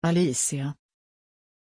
Pronunția numelui Alícia
pronunciation-alícia-sv.mp3